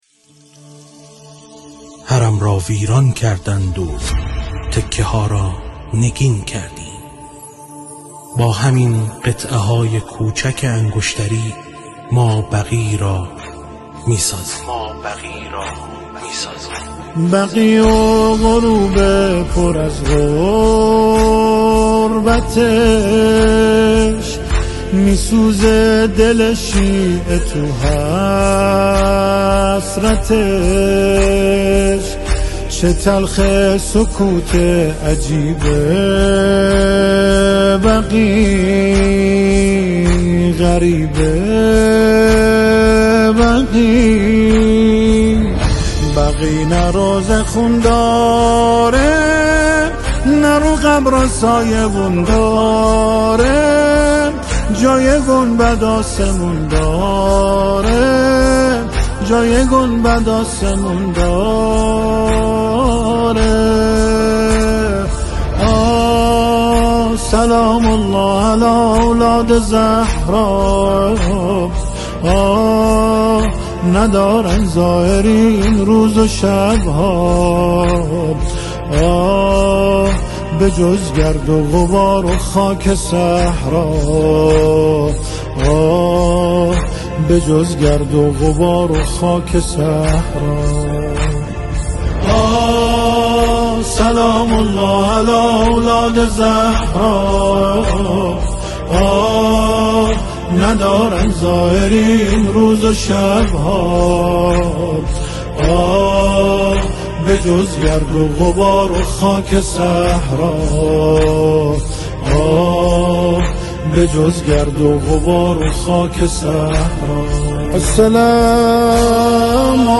ضبط شده در: استودیو نور یاس